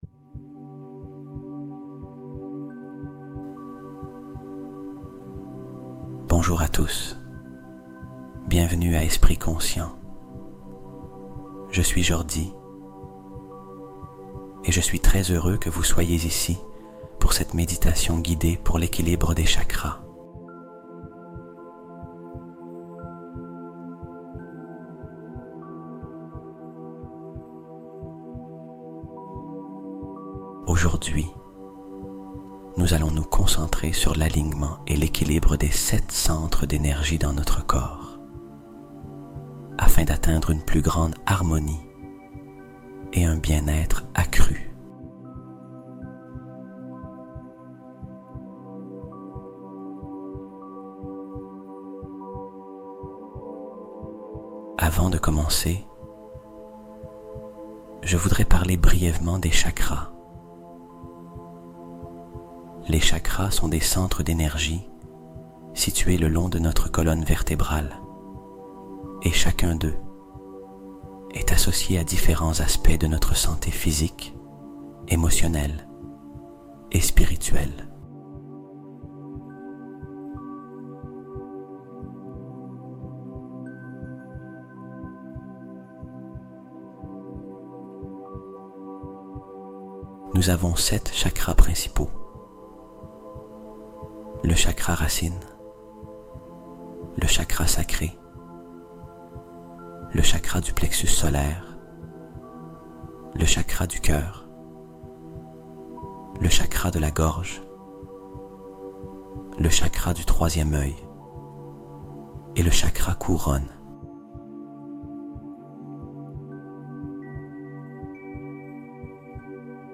Équilibre Tes 7 Chakras en 30 Minutes : Méditation Complète Pour Une Harmonie Totale